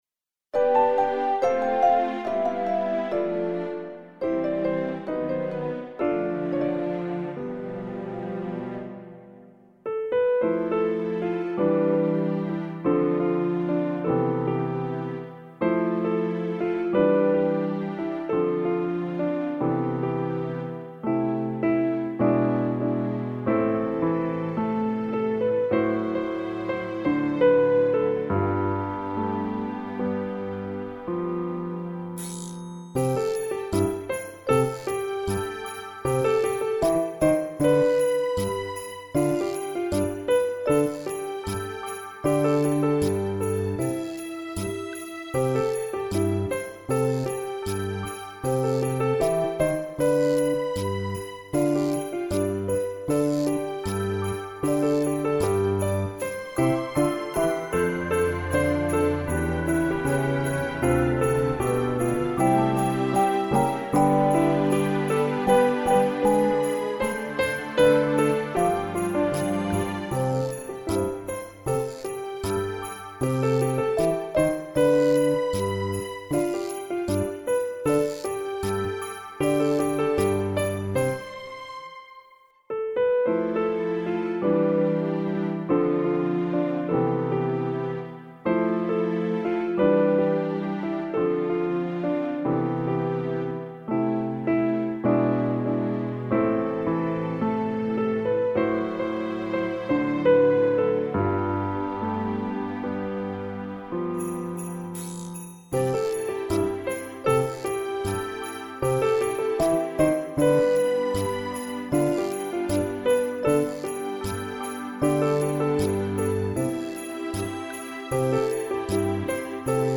Chant de Noël, France.
Télécharger mp3 instrumental pour Karaoké
instrumental
4/4 – 155 bpm